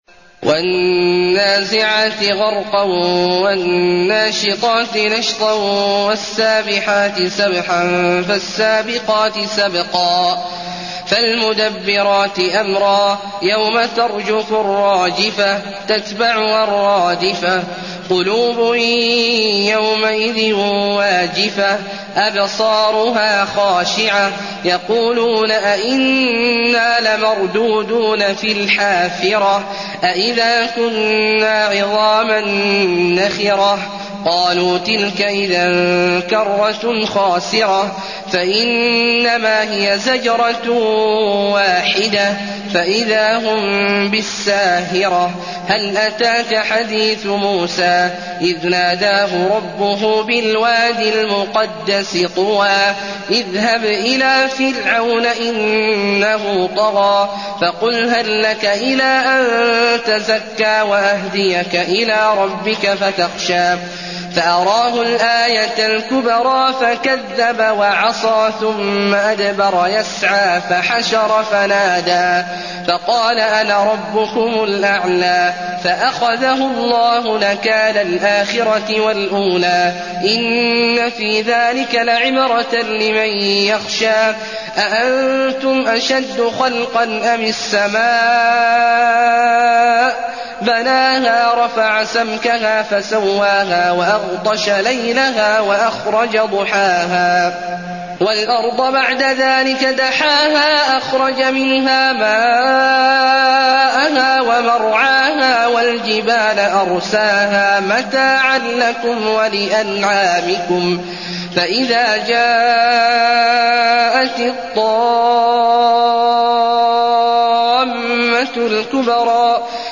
المكان: المسجد النبوي النازعات